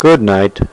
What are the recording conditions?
Download a high-quality good night sound effect.